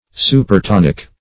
(music) the second note of a diatonic scale ; The Collaborative International Dictionary of English v.0.48: Supertonic \Su`per*ton"ic\, n. (Mus.)